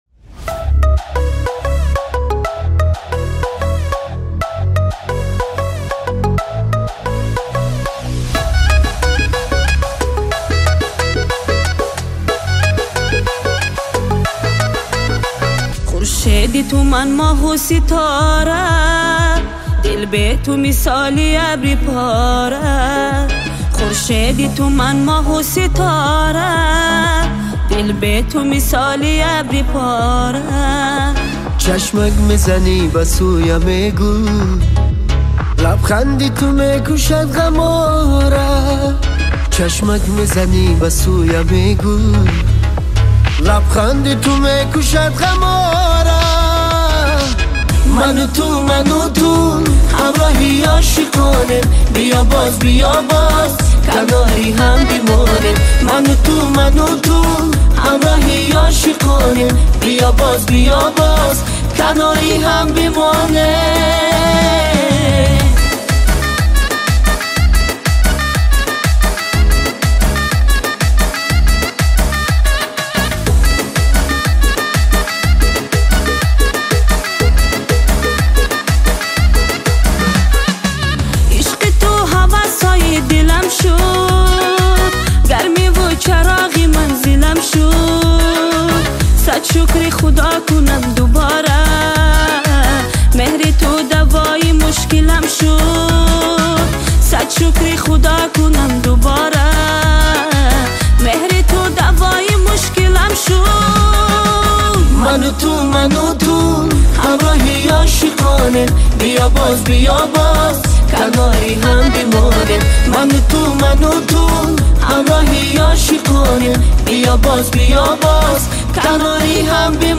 • Категория: Таджикские песни